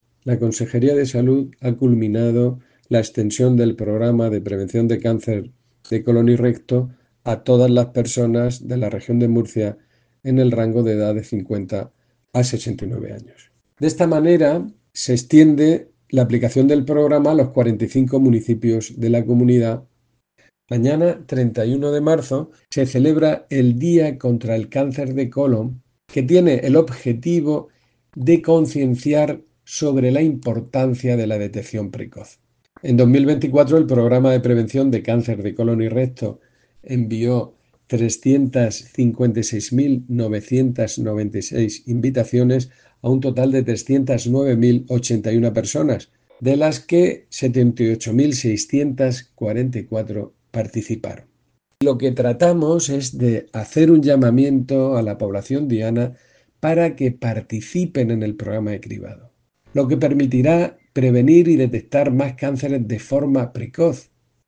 Sonido/ Declaraciones del director general de Salud Pública y Adicciones, José Jesús Guillén, sobre la importancia de la detección precoz del cáncer de colon.